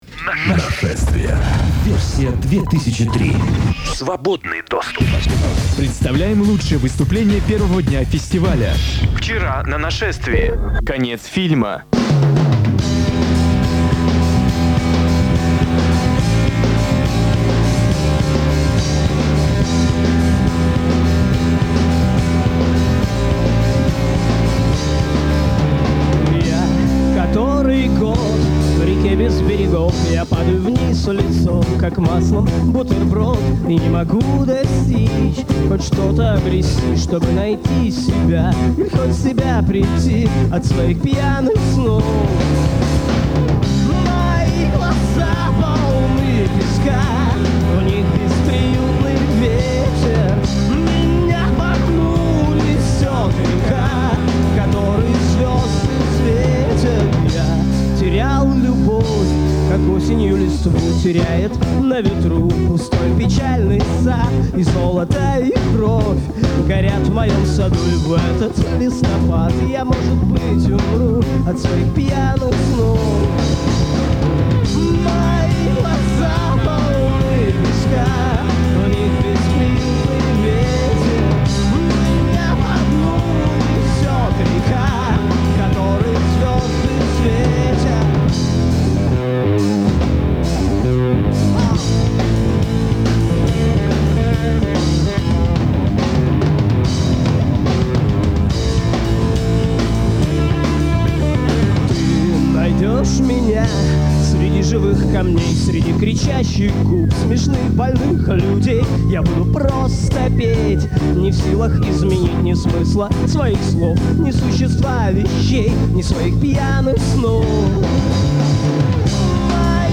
Дописка на кассете